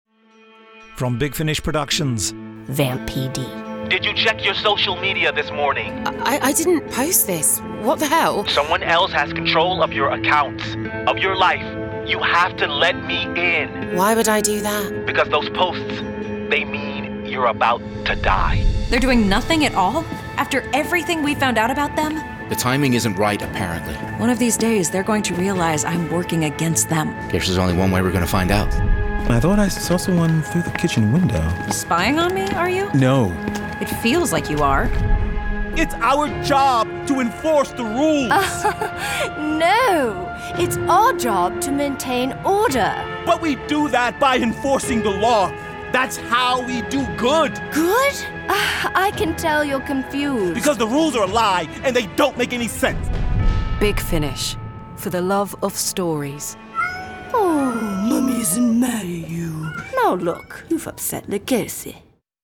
Award-winning, full-cast original audio dramas from the worlds of Doctor Who, Torchwood, Blake's 7, Class, Dark Shadows, The Avengers, Survivors, The Omega Factor, Star Cops, Sherlock Holmes, Dorian Gray, Pathfinder Legends, The Prisoner, Adam Adamant Lives, Space 1999, Timeslip, Terrahawks, Space Precinct, Thunderbirds, Stingray, Robin Hood, Dark Season, UFO, Stargate, V UK, Time Tunnel
VAM PD Volume 02 Released February 2025 Written by Aaron Lamont Ash Darby Rochana Patel David Smith Starring Juliet Landau Mara Wilson This release contains adult material and may not be suitable for younger listeners. From US $25.01 Download US $25.01 Buy Save money with a bundle Login to wishlist 8 Listeners recommend this Share Tweet Listen to the trailer Download the trailer